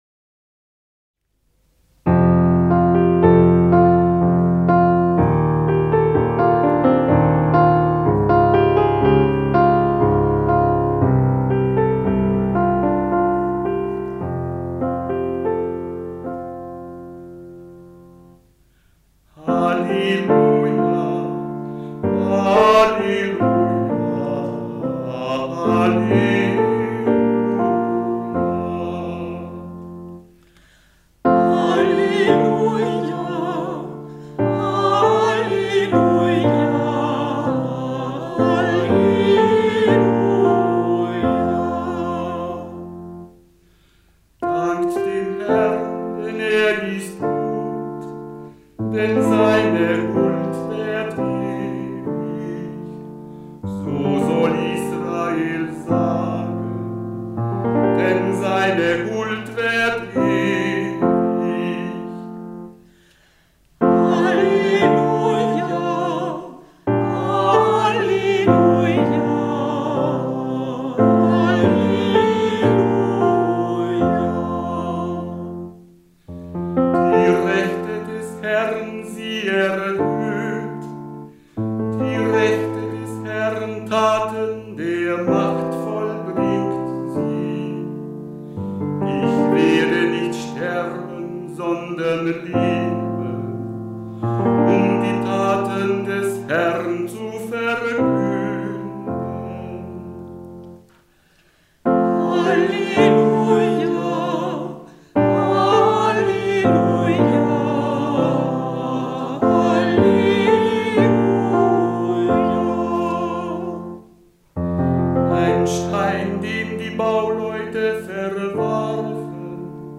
Psalm 118 - Hirt B S. 68, VI Ton, neue Aufnahme 2 MB 3./4.